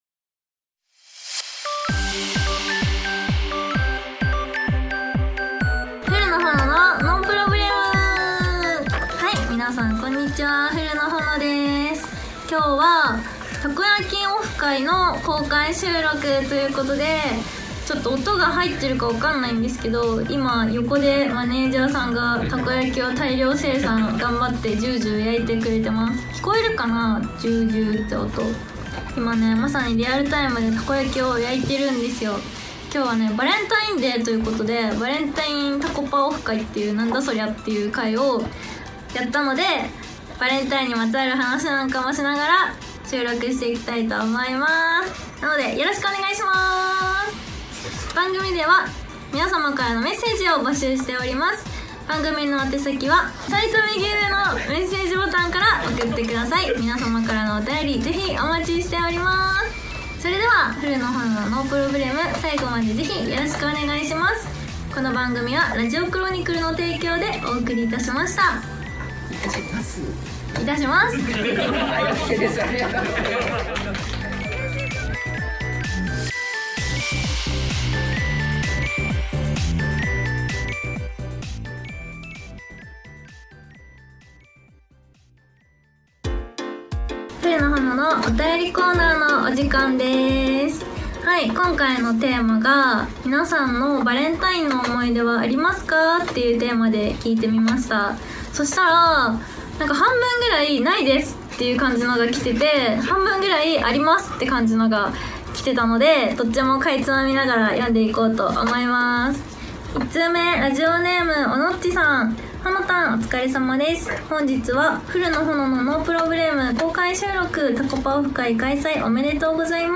2/14にバレンタインタコパオフ会＆公開収録が開催されました！